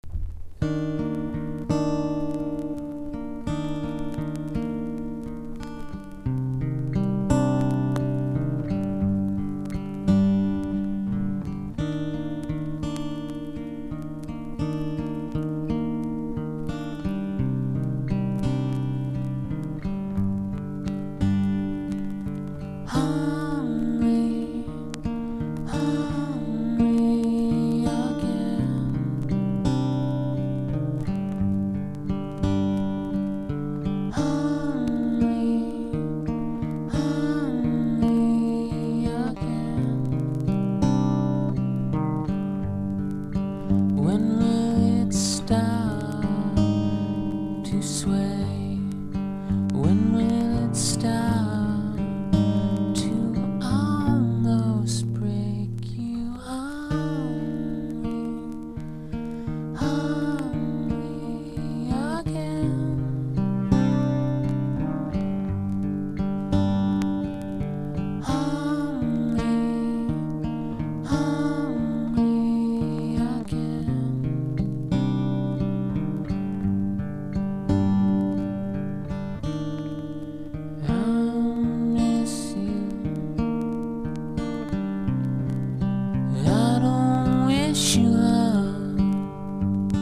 1. 90'S ROCK >
ALTERNATIVE / GRUNGE